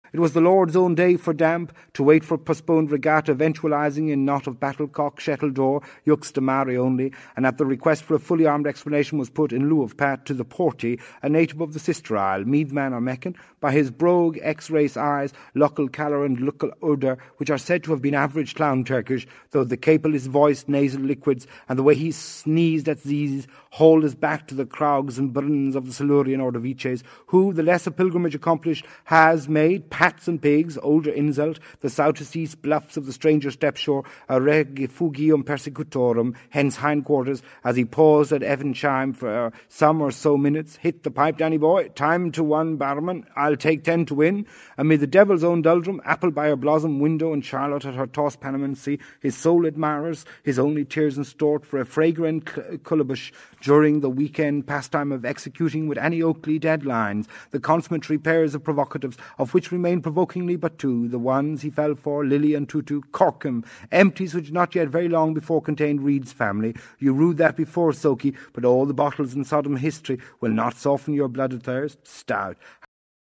the stammering, the monotonous drone, the break-neck speed at which he mumbles out the text, etc.